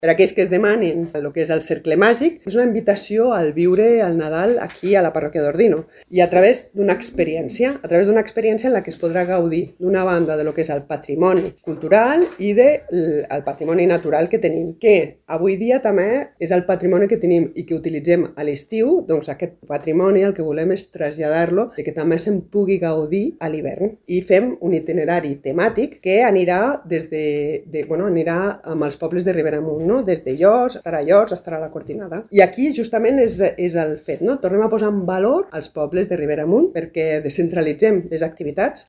La cònsol major, Maria del Mar Coma, ha explicat que el projecte vol descentralitzar les activitats, que fins ara es concentraven al centre d’Ordino, i revitalitzar els pobles de Riberamunt.